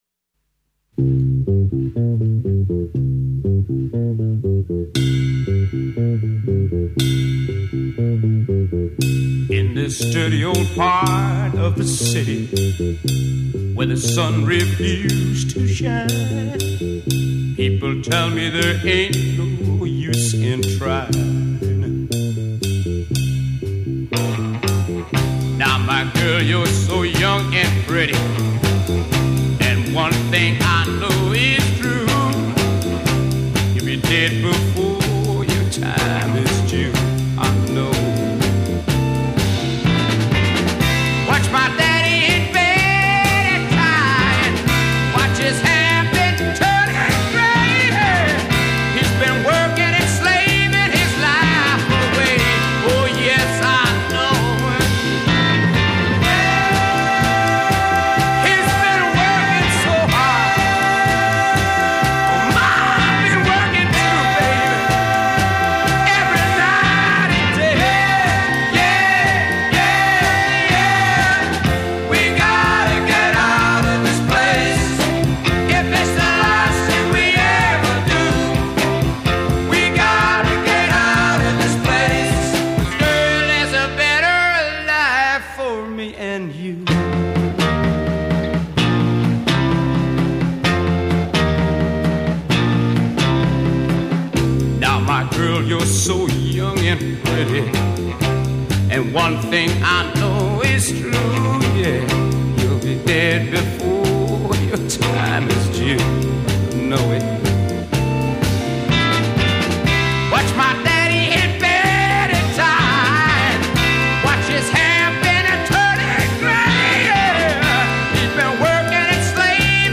Recorded at Kingsway Studios, 1965.
Intro 0:00 4 bass solo (add cymbal)
A verse : 8 voice, organ, guitar, bass, & cymbal c
A' bridge : 8 build intensity d
British Blues